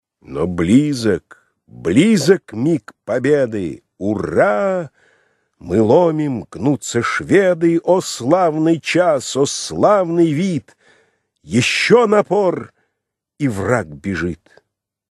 файл) 159 Кб Поэма «Полтава» А.С.Пушкина. Песнь третья (отрывок). Худ. чтение 1